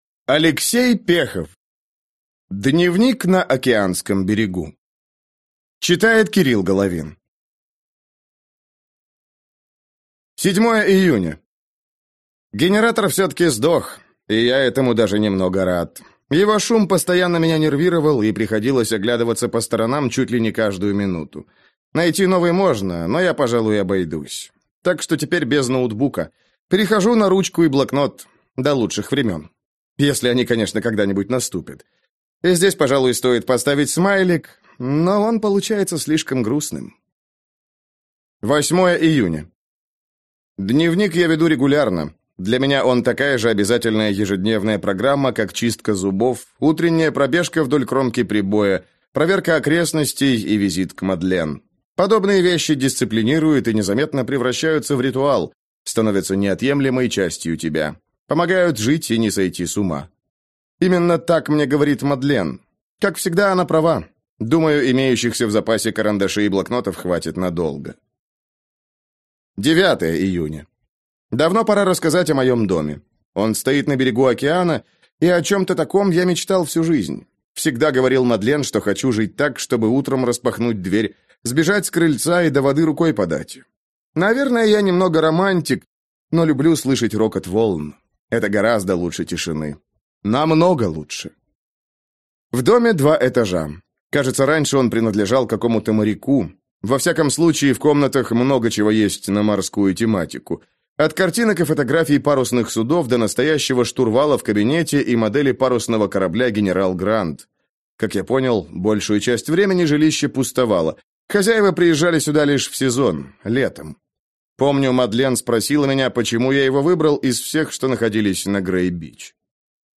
Аудиокнига Дневник на океанском берегу - купить, скачать и слушать онлайн | КнигоПоиск